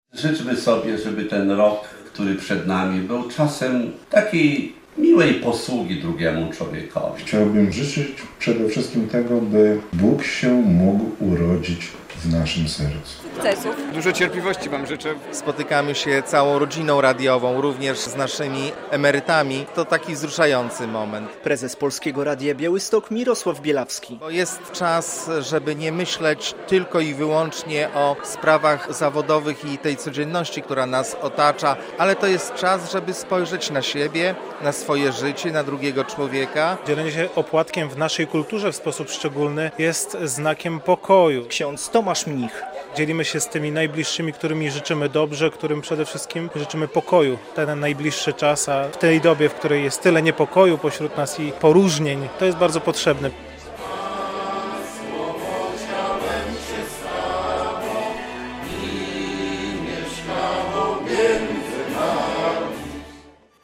Była to okazja do składania sobie życzeń i śpiewania kolęd. W Polskim Radiu Białystok przed świętami Bożego Narodzenia podzieliliśmy się opłatkiem, który zgodnie z tradycją jest symbolem braterstwa i pojednania.
składają życzenia pracownikom Polskiego Radia Białystok